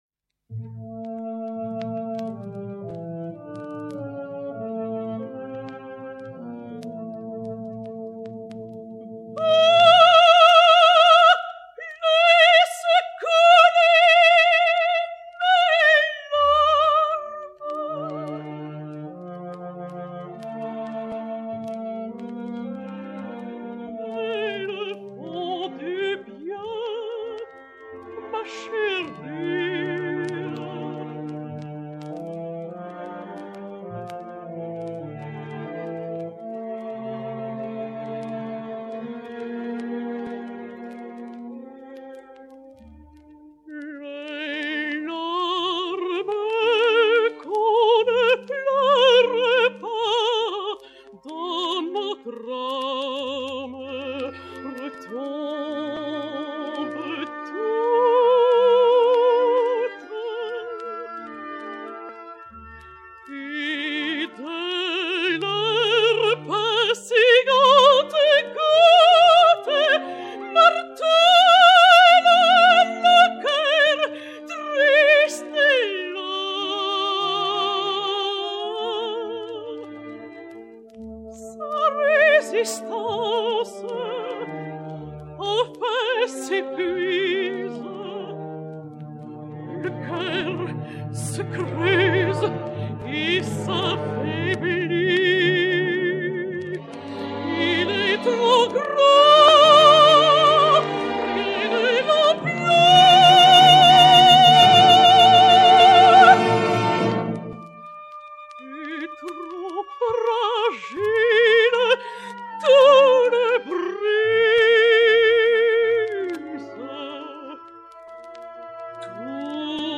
mezzo-soprano belge